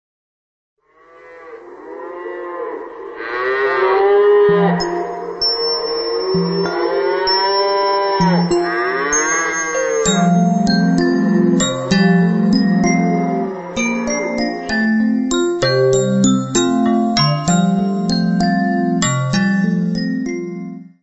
: stereo; 12 cm
Área:  Fonogramas Infantis